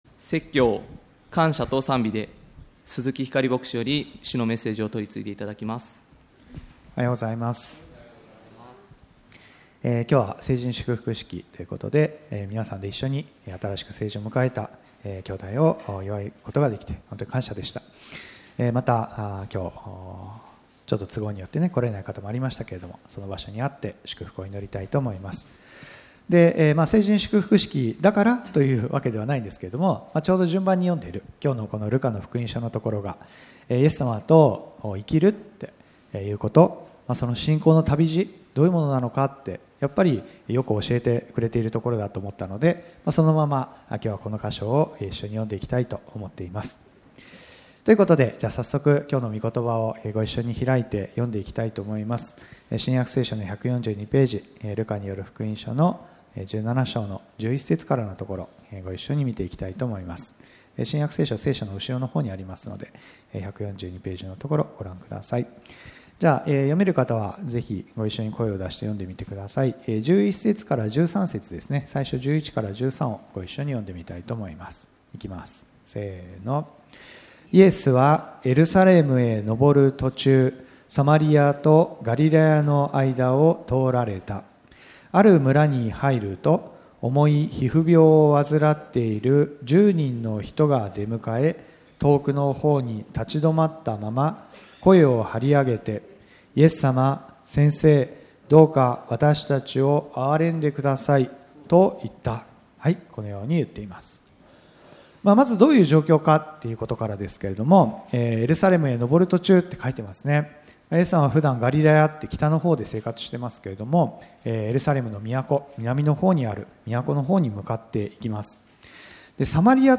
ルカによる福音書17章11～19節 牧師